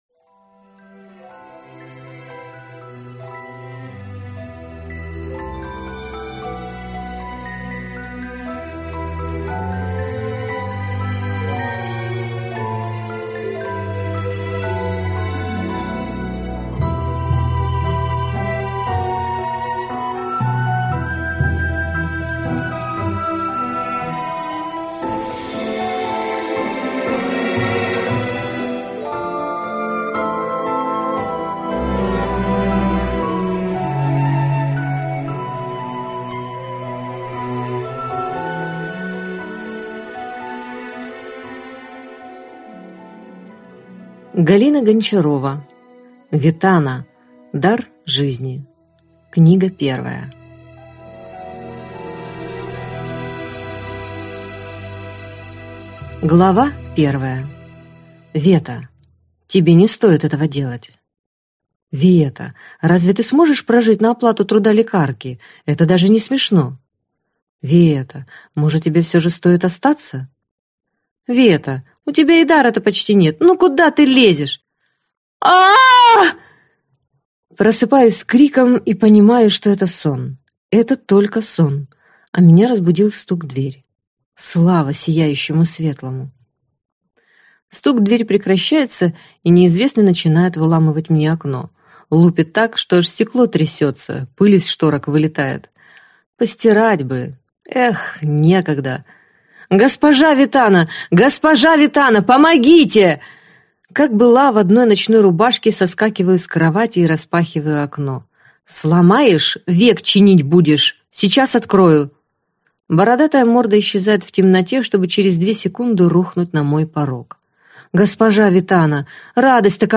Аудиокнига Ветана.